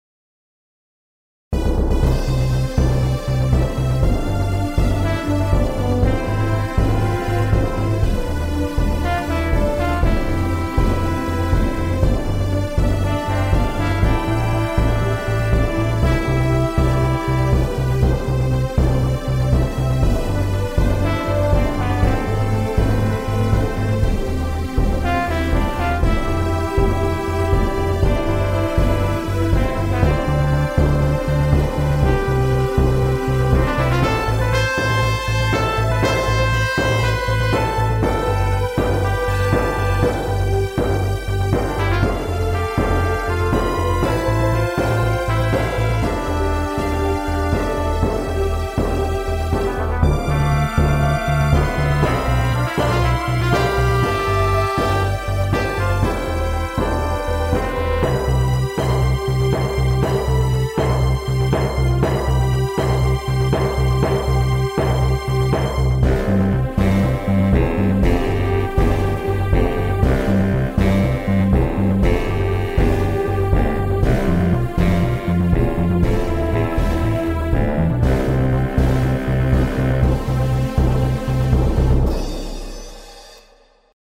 インストゥルメンタルショート暗い激しい